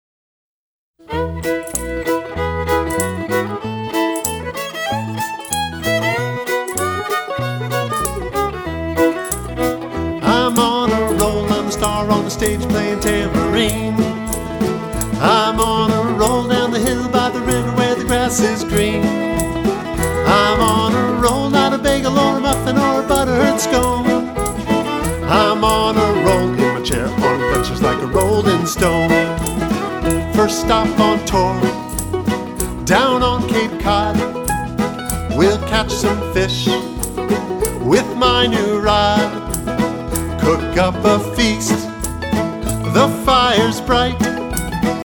With a youthful, warm and energetic style